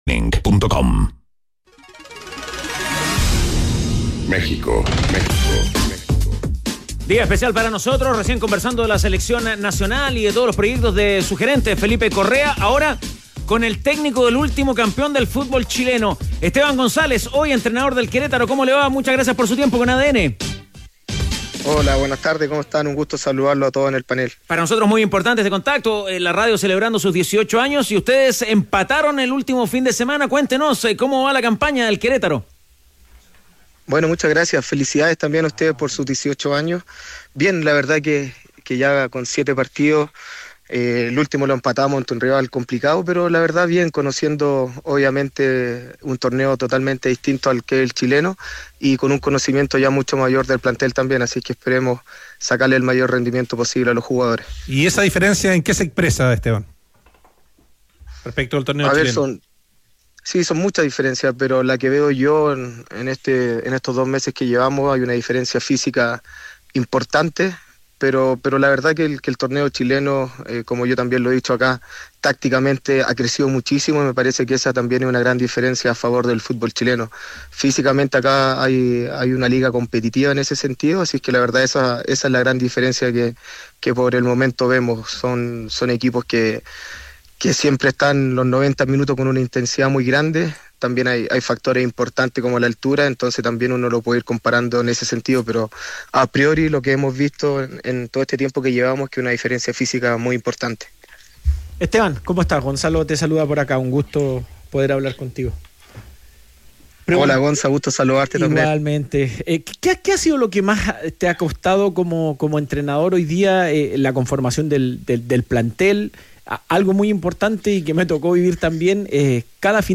En conversación con Los Tenores